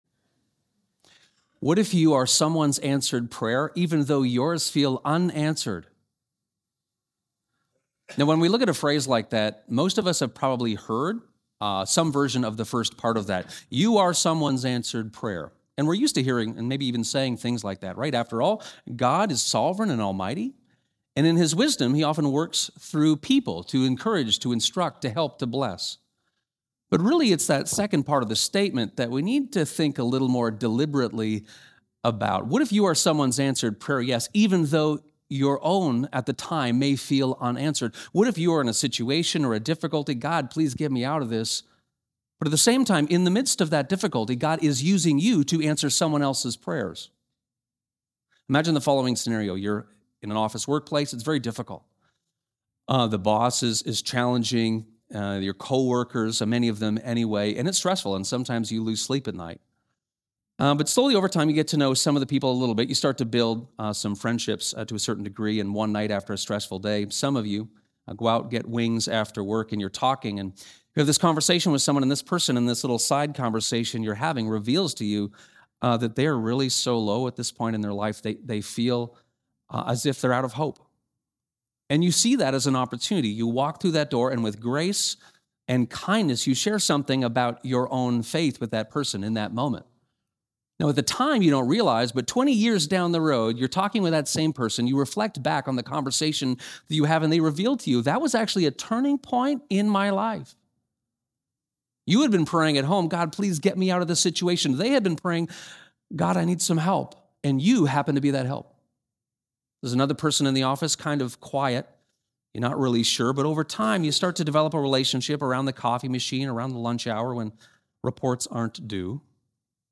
This sermon unpacks Daniel 5, the famous “writing on the wall” for King Belshazzar, and how Daniel’s ongoing and steadfast faithfulness instructs people today who are enduring difficulty they don’t understand.